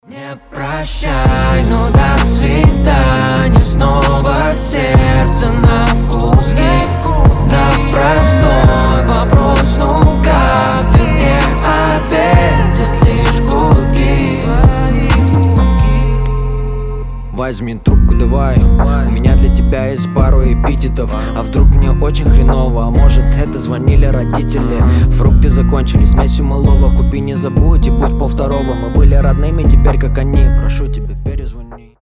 • Качество: 320, Stereo
мужской голос
лирика
спокойные